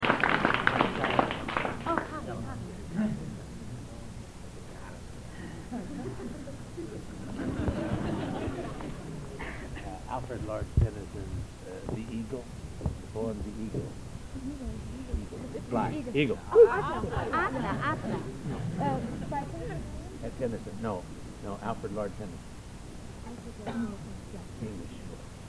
Collection: Bückeburg Garden Party